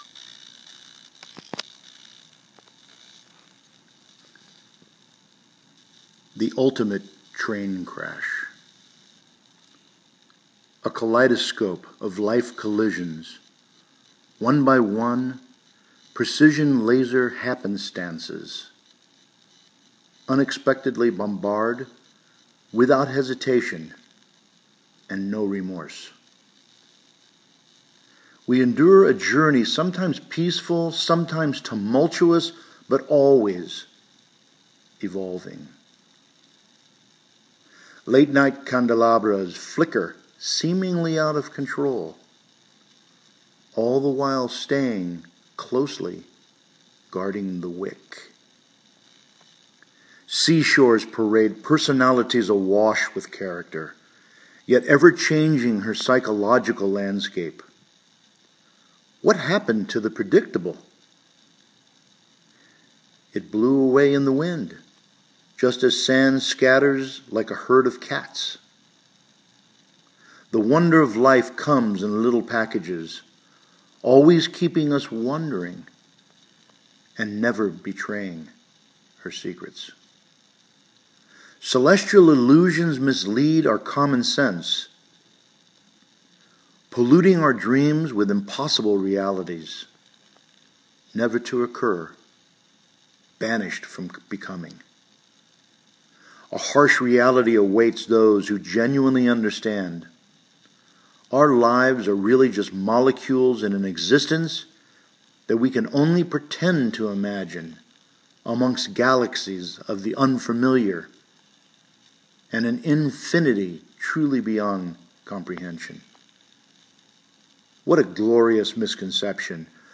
To listen to a reading of the poem by the poet, click on the player below: